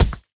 Player Boulder Drop Sound Effect
Download a high-quality player boulder drop sound effect.
player-boulder-drop.mp3